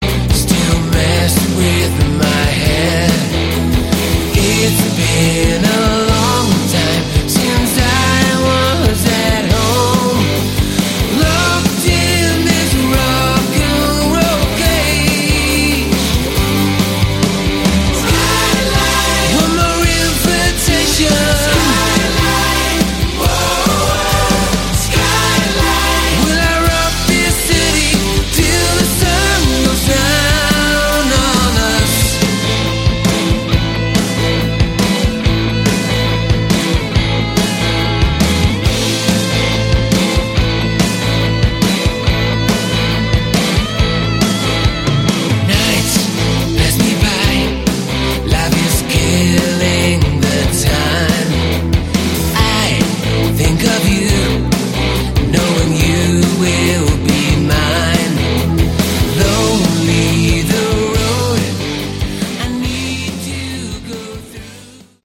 Category: AOR
lead and backing vocals
acoustic, electric guitars, keyboards
drums, percussion, synthesizers, keyboards